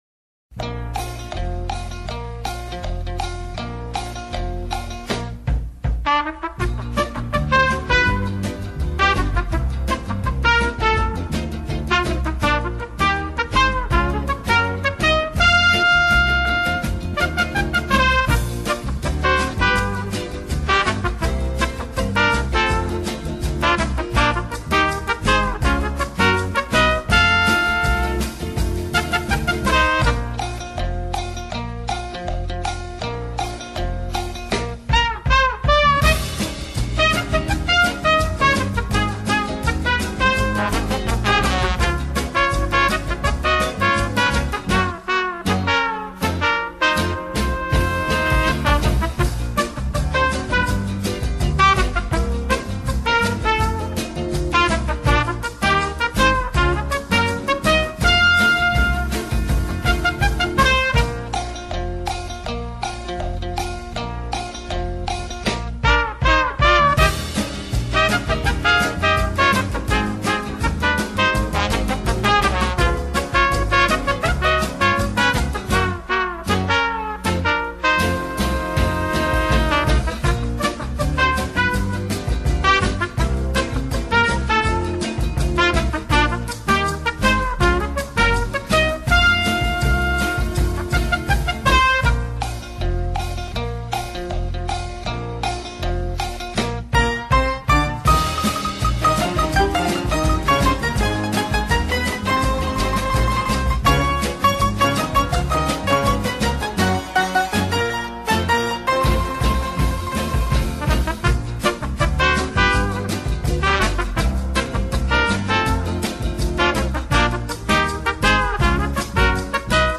Комедийная мелодия для танца блохи